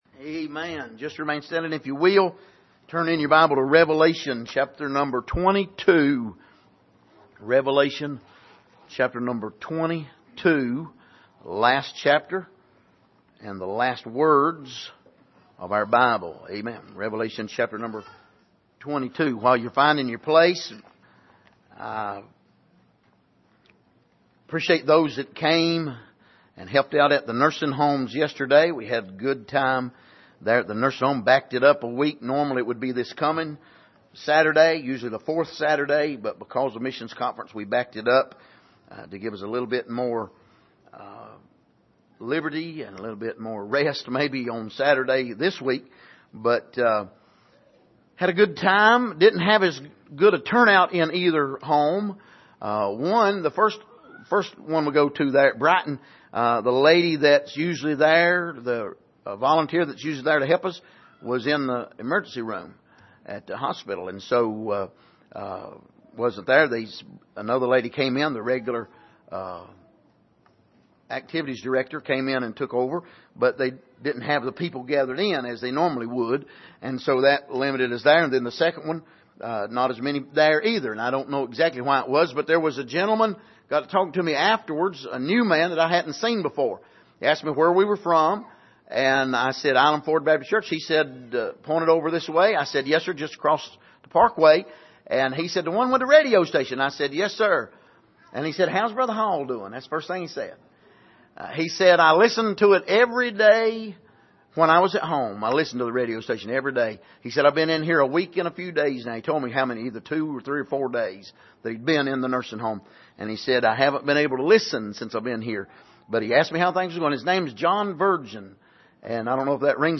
Passage: Revelation 22:20-21 Service: Sunday Morning